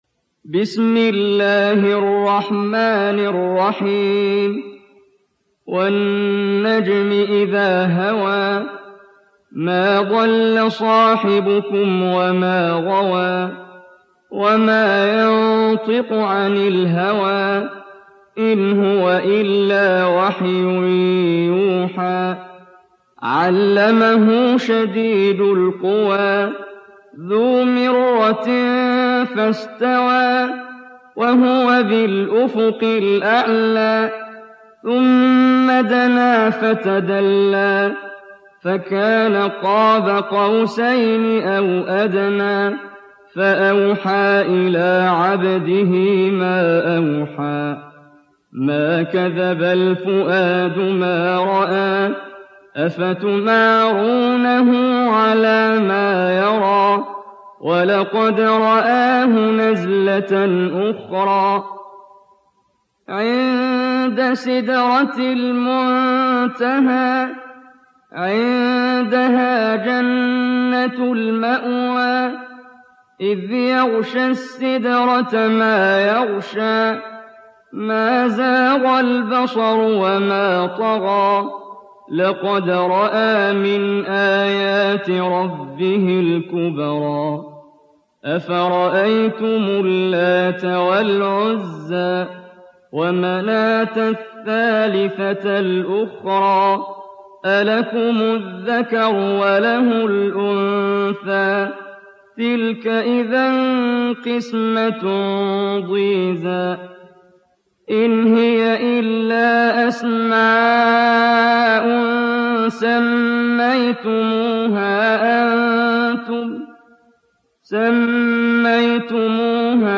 تحميل سورة النجم mp3 بصوت محمد جبريل برواية حفص عن عاصم, تحميل استماع القرآن الكريم على الجوال mp3 كاملا بروابط مباشرة وسريعة